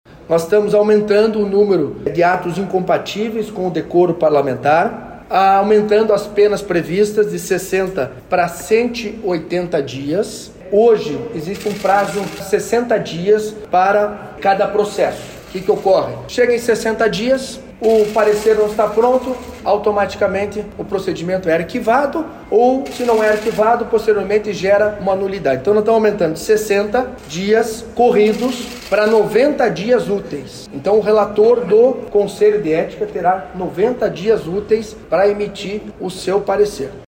O presidente da Assembleia também falou sobre prazos e penas que foram alterados no novo código.